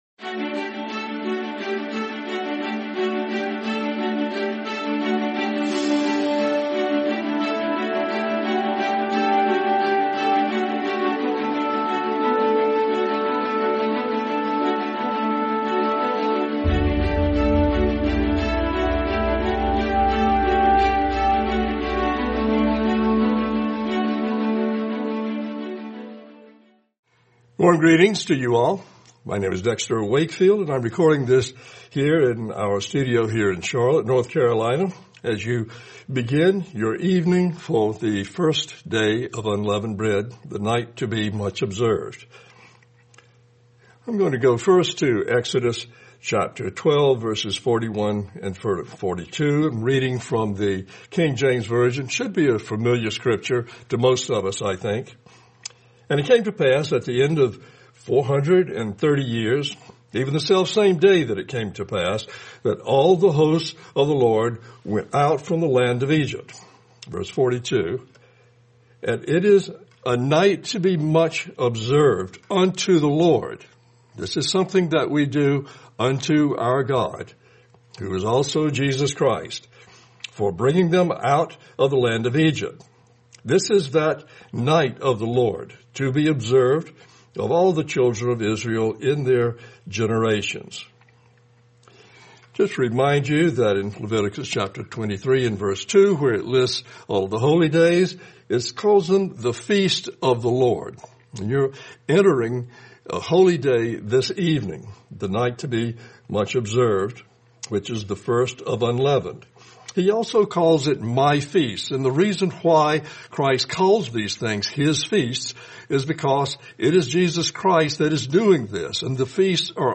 Sermon Night to Be Much Observed: 2026